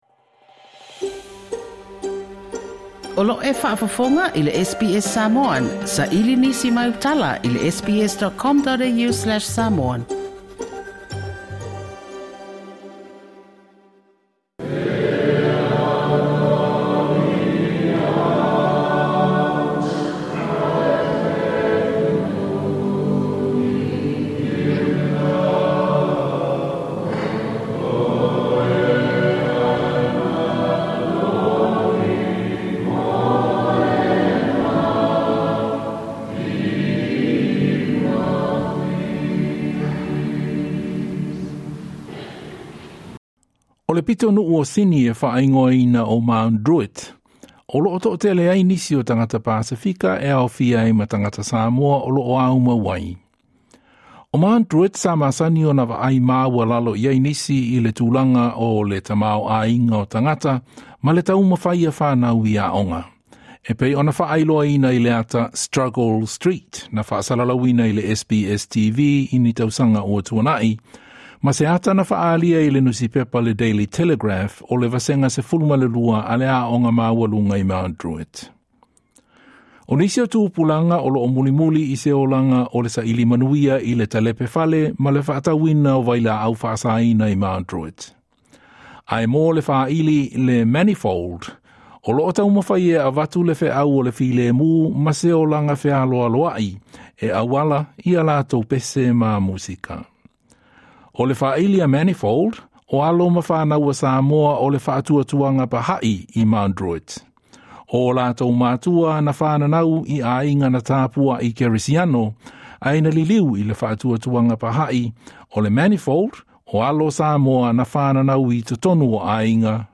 We had the pleasure of spending a Sunday at the Baha'i temple in Mona Vale and chatting to these amazing young people from the band Manifold.
Their music is a mix of Rap, R&B, Gospel and Polynesian reggae and they CAN sing harmony beautifully. They told me that their songs are about presenting a positive look and hope for Mt Druitt and western Sydney, not the negative depictions of 'Struggle Street' and the street gangs and drugs.